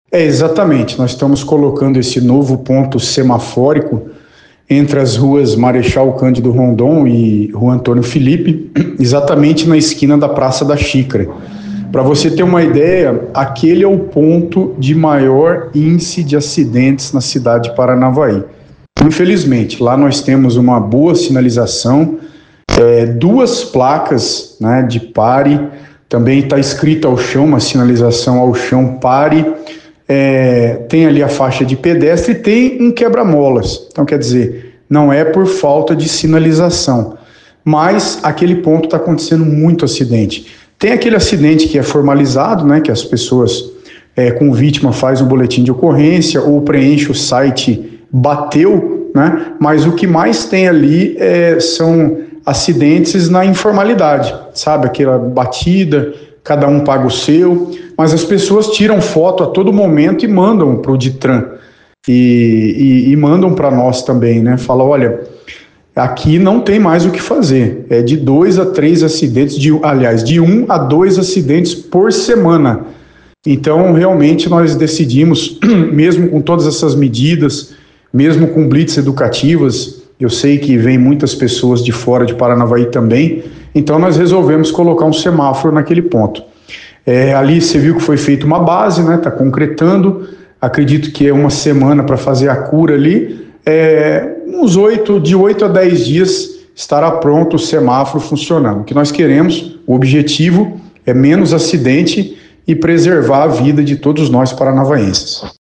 A Secretaria de Segurança e Trânsito de Paranavaí (Ditran) iniciou a implantação de um novo conjunto semafórico no cruzamento das ruas Marechal Cândido Rondon e Antônio Felipe, na esquina da Praça da Xícara, centro da cidade. A medida, confirmada pelo secretário Ademir Giandotti Júnior em entrevista ao BNP, visa frear o alto índice de colisões no local, com previsão de funcionamento total entre 8 a 10 dias.